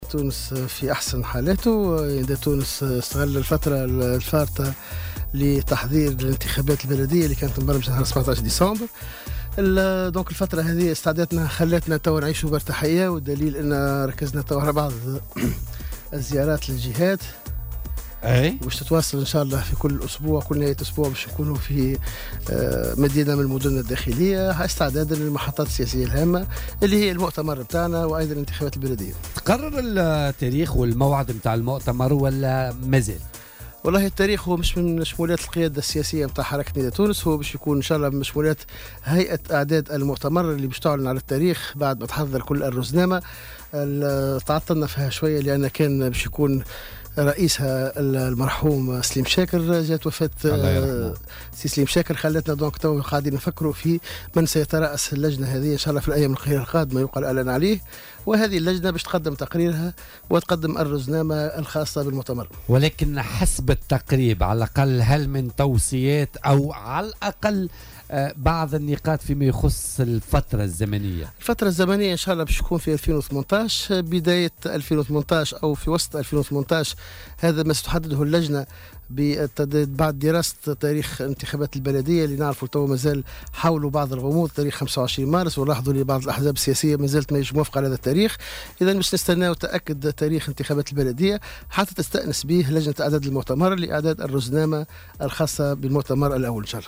قال رئيس كتلة نداء تونس بمجلس نواب الشعب، سفيان طوبال إن "الحزب في أحسن حال" و إن قياداته منشغلة في الإعداد للانتخابات البلدية و لعقد مؤتمره الانتخابي. وأضاف طوبال، ضيف برنامج "بوليتيكا" اليوم أن تحديد موعد المؤتمر ليس من مشمولات القيادة السياسية وإنما من مشمولات هيئة إعداد المؤتمر و التي سيتم الإعلان عن رئيسها قريبا، وفق تعبيره.